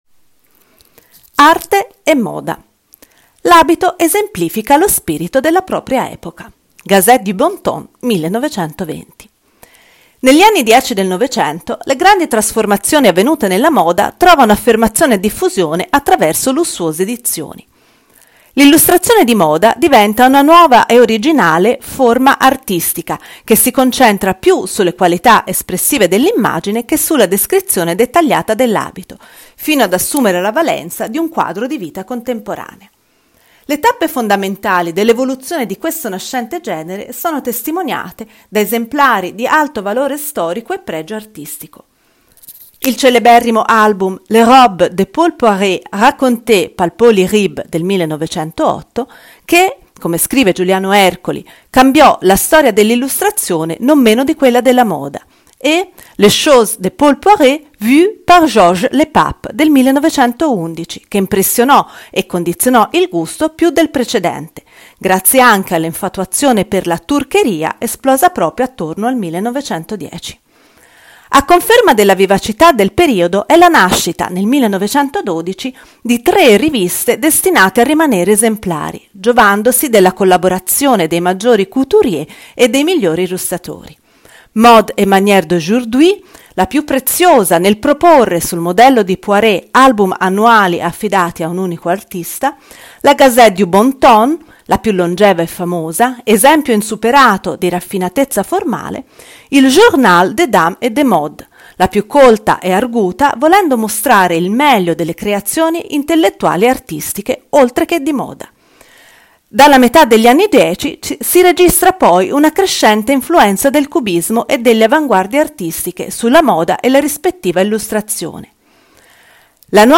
AUDIOGUIDA MOSTRA